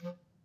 DCClar_stac_F2_v1_rr1_sum.wav